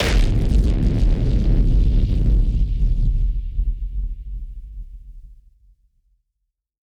BF_HitSplosionB-02.wav